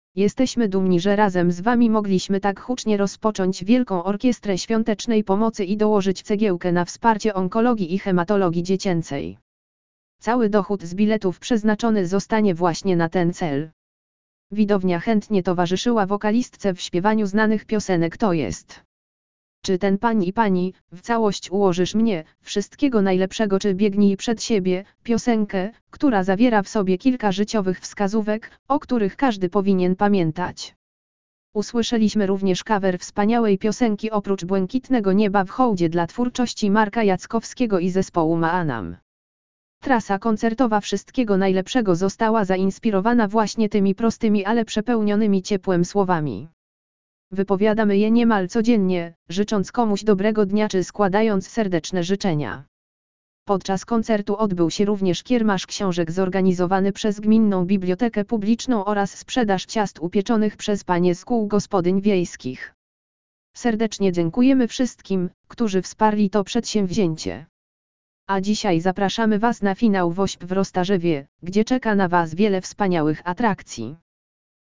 Widownia chętnie towarzyszyła wokalistce w śpiewaniu znanych piosenek tj.